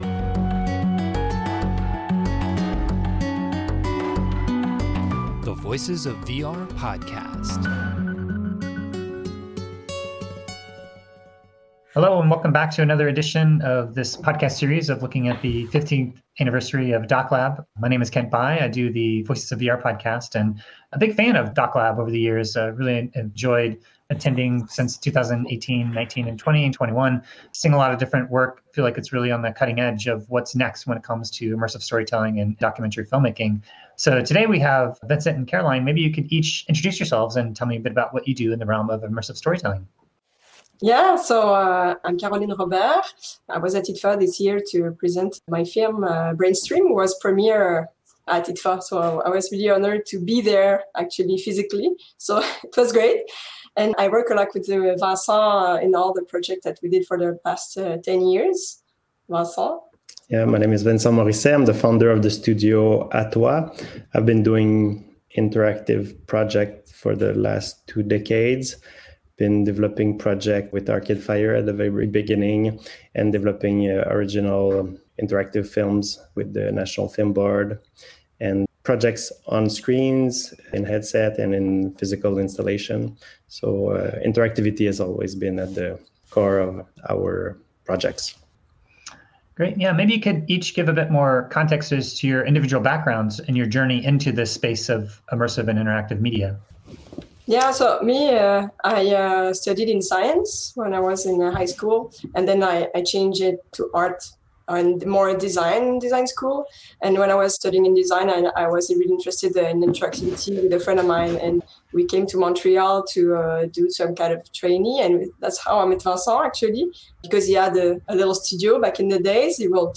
This was recorded on Friday, December 3, 2021 as a part of a collaboration with IDFA’s DocLab to celebrate their 15th year anniversary.